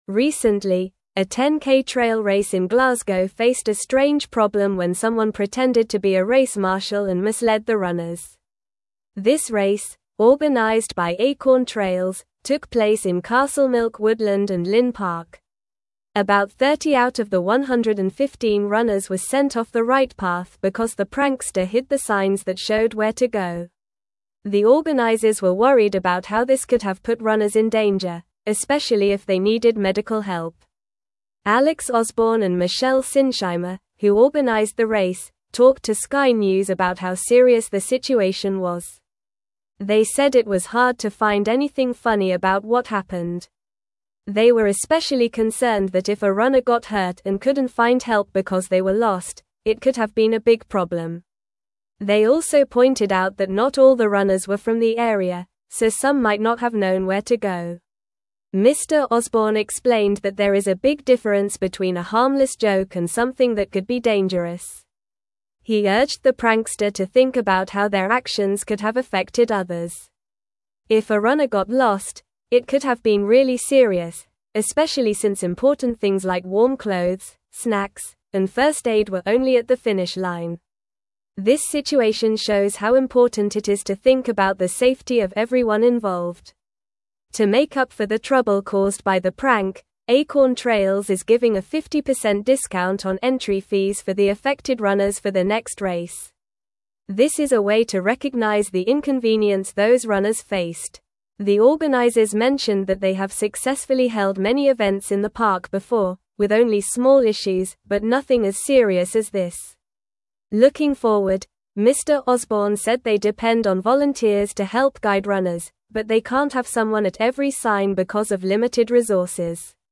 Normal
English-Newsroom-Upper-Intermediate-NORMAL-Reading-Prankster-Disrupts-Glasgow-10k-Trail-Race-Direction.mp3